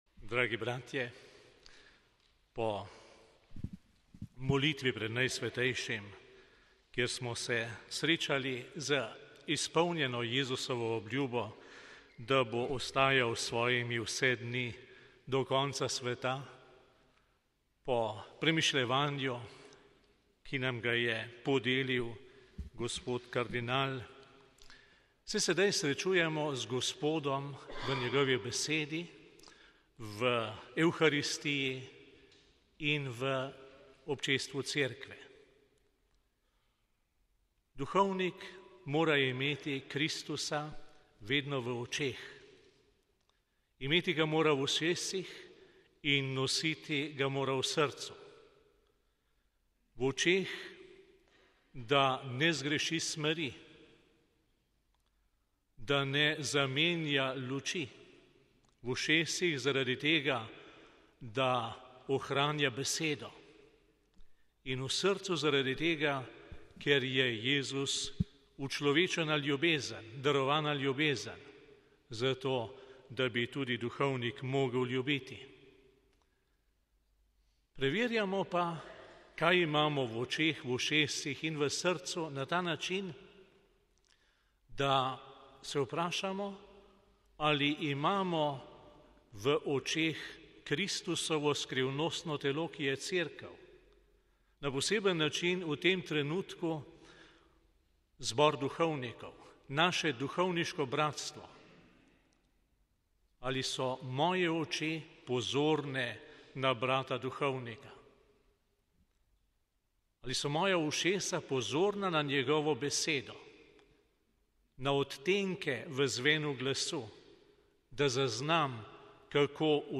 Uvod v mašo